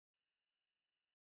silence.mp3